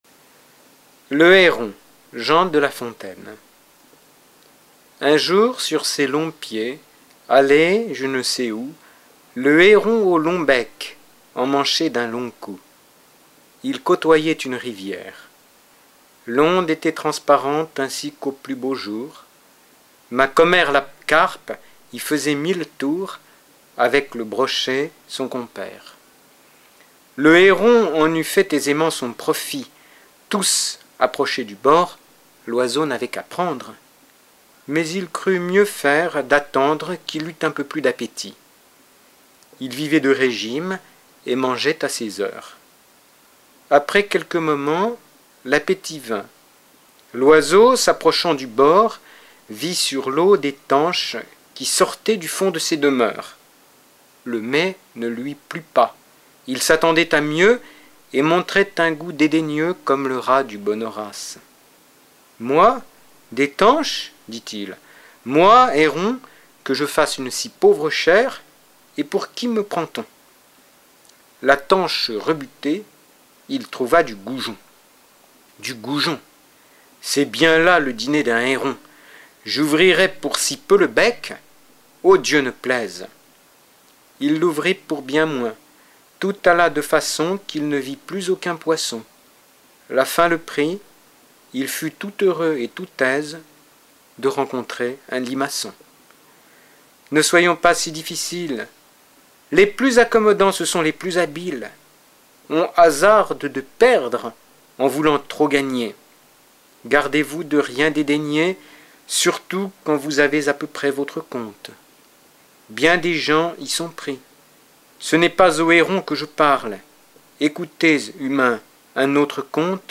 フランス人による朗読音声